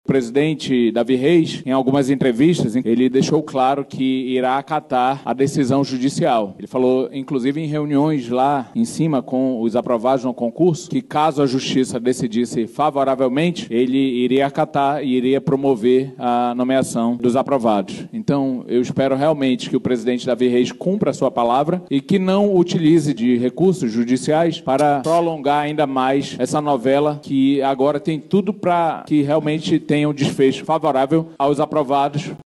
Assim que tomou conhecimento da decisão judicial, o vereador Rodrigo Guedes, do Progressistas, se pronunciou na Câmara e disse que espera que presidente da Casa Legislativa acate a decisão judicial.